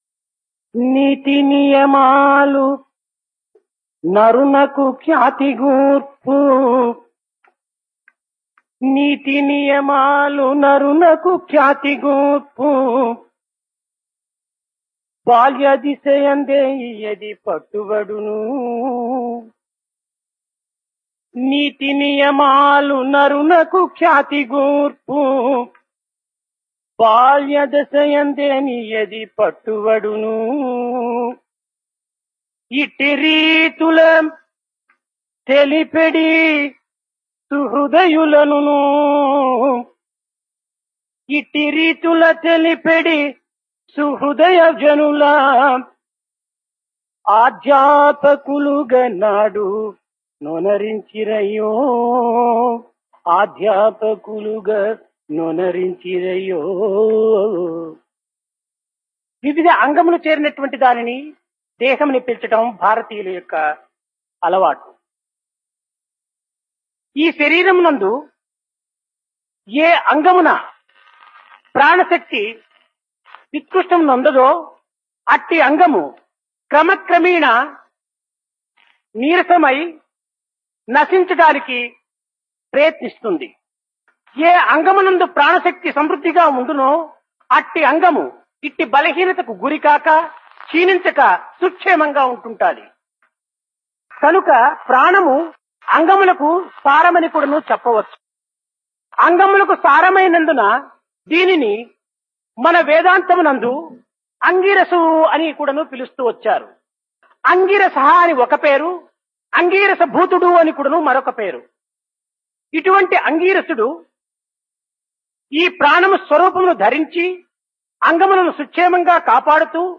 Divine Discourse of Bhagawan Sri Sathya Sai Baba, Summer Showers 1974 Date: May 1974 Occasion: Summer Course 1974 - Brahman